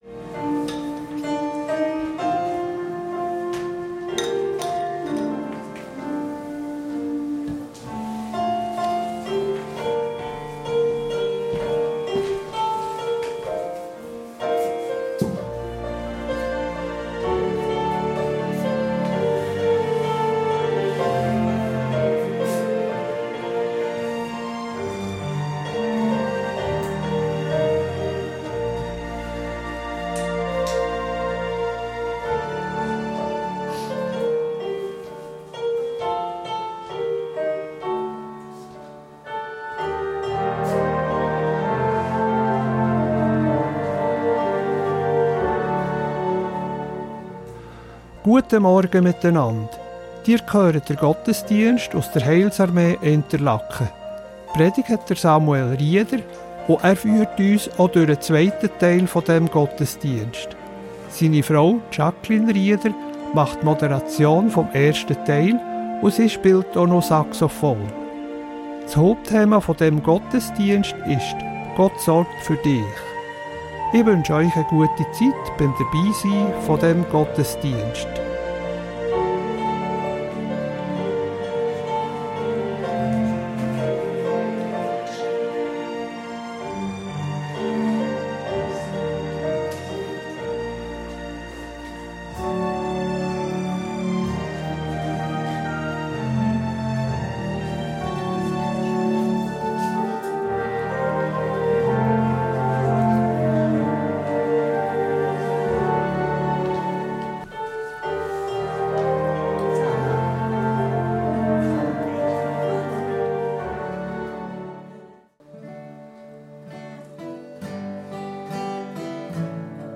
Heilsarmee Interlaken ~ Gottesdienst auf Radio BeO Podcast